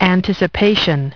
[an-tis-uh-pey-shuh n]